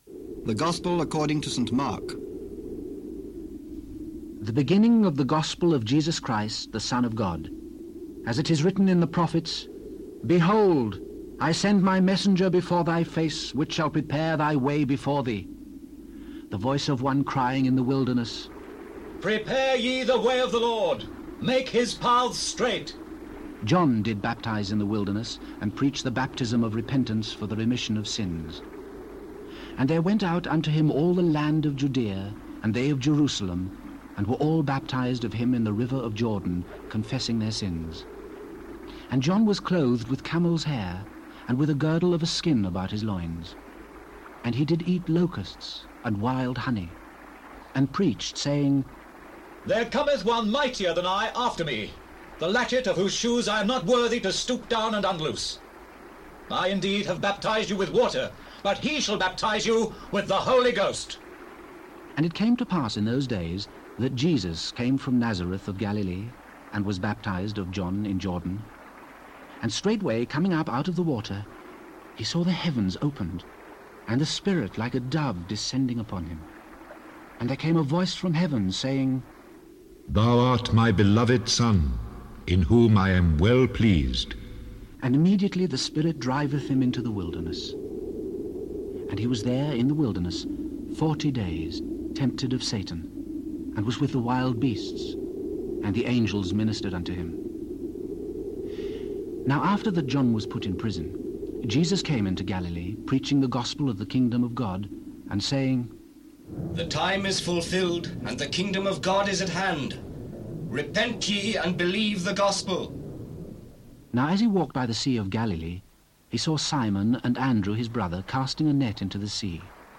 This is a dramatized audio version of the Bible, performed by the Royal Shakespeare Company. It was originally released on cassette tapes, but is no longer in circulation and thus hard to find.
Fortunately, someone digitized it.